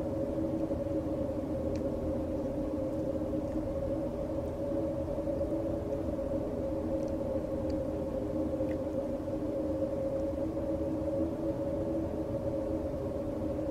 白噪声楼道1.ogg